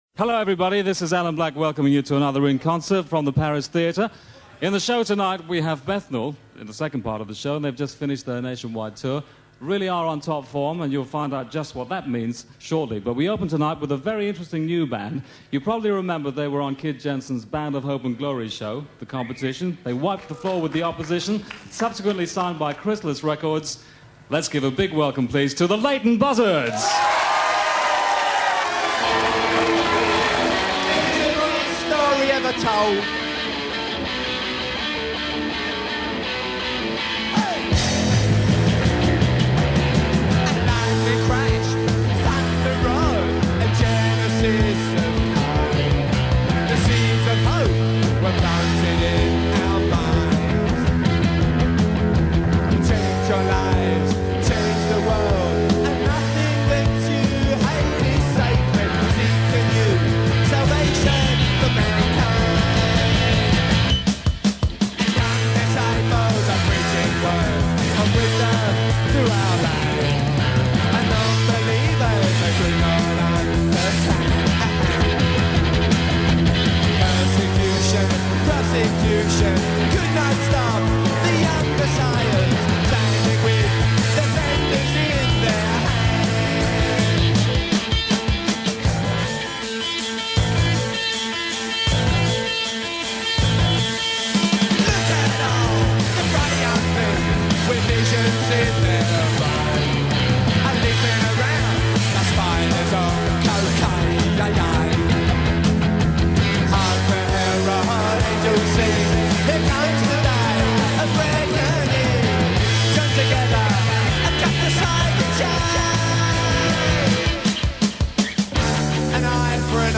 In Concert from The Paris Theatre, London
In Concert at The Paris Theatre
Short lived Punk band from the East London suburb of Leyton.
Punk/New-Wave